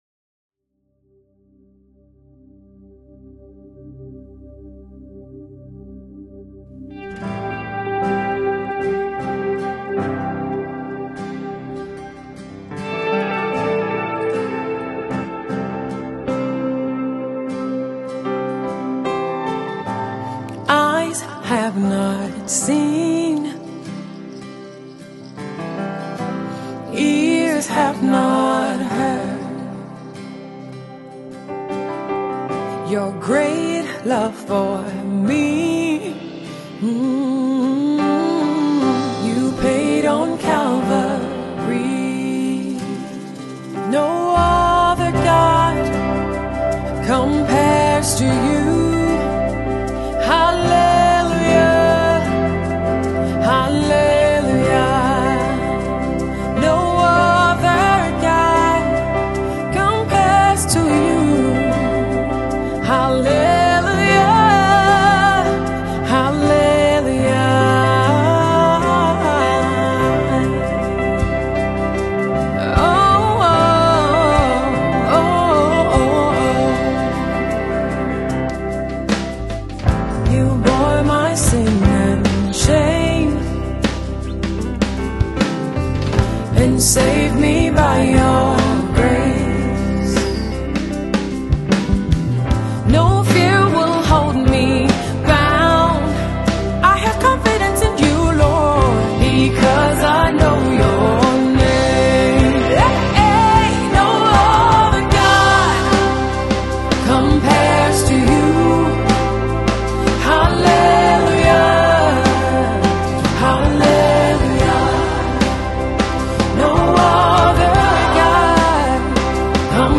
American raised Gospel artiste currently residing in Houston